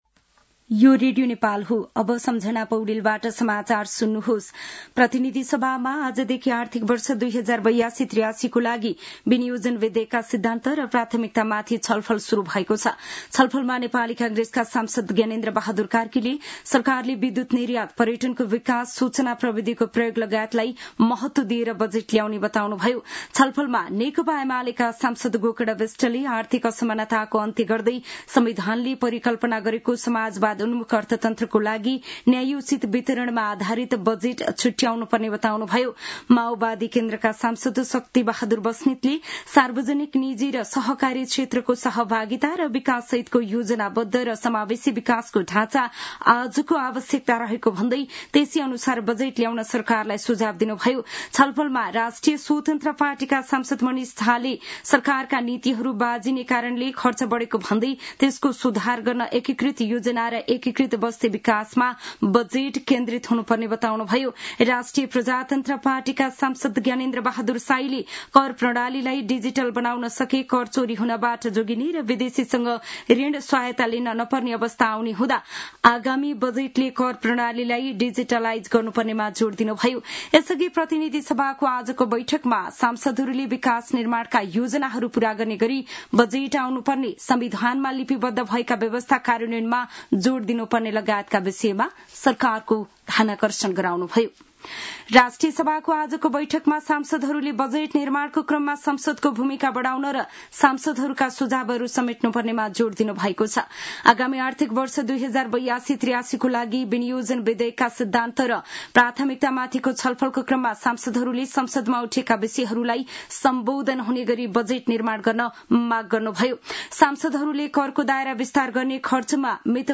दिउँसो ४ बजेको नेपाली समाचार : ३० वैशाख , २०८२
4-pm-news-.mp3